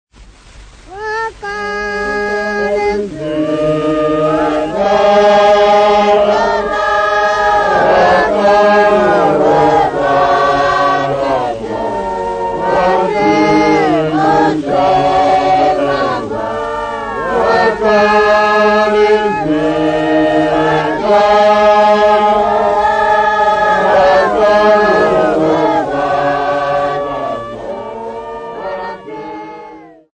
Choir at Ekupakameni Church of Nazaretha, Shembe
Folk Music
Field recordings
sound recording-musical
Indigenous music
96000Hz 24Bit Stereo